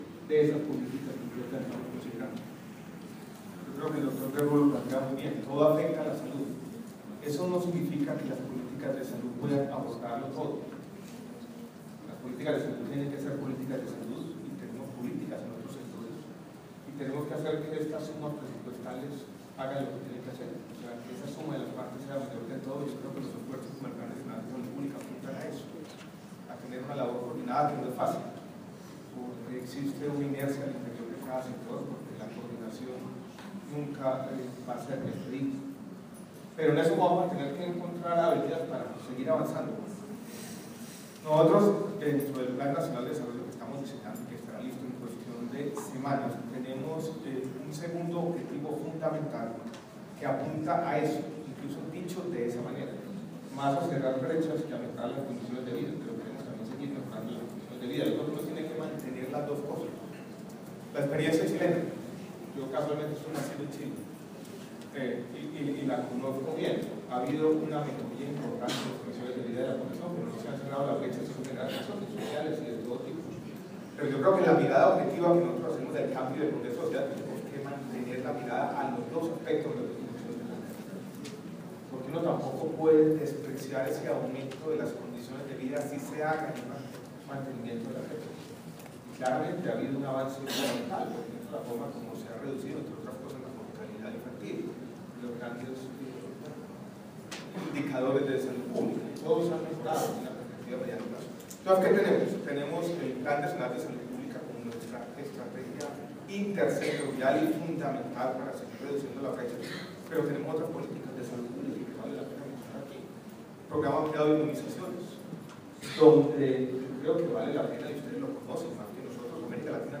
Audio, MinSalud habla sobre políticas para cerrar las brechas en salud
Cali, 25 de octubre de 2014.- En el marco del Primer Encuentro Latinoamericano de Salud Pública el Ministro de Salud y Protección Social, Alejandro Gaviria Uribe, intervino en el panel sobre la incorporación  de una visión de determinantes sociales para la generación de políticas públicas para el abordaje de inequidades y señaló que el positivismo es una ideología esencial en la construcción de políticas públicas.